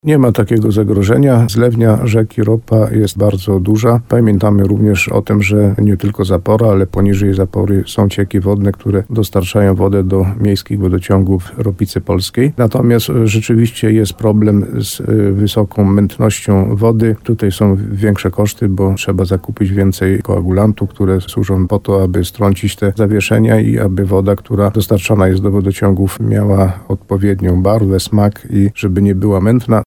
Włodarz gminy Ropa w programie Słowo za Słowo na antenie RDN Nowy Sącz uspokajał te nastroje.